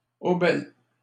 Aubel (French pronunciation: [obɛl]